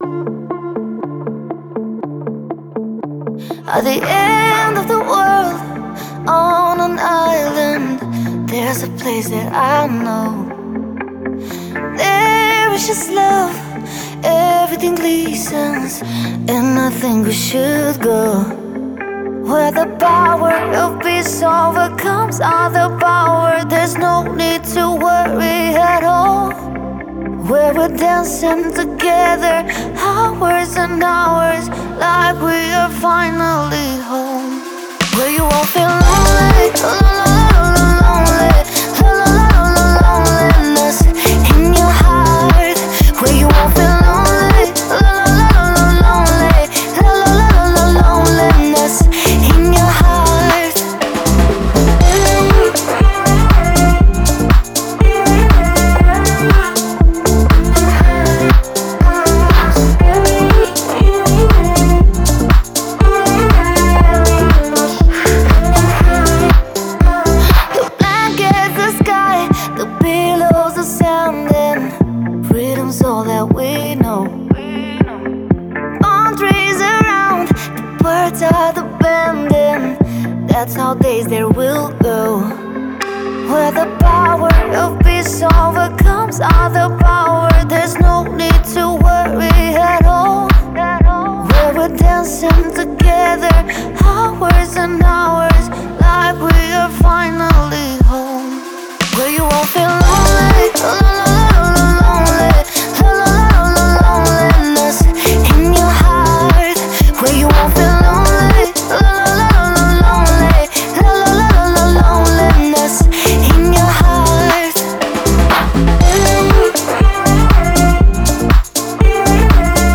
это трек в жанре электронная музыка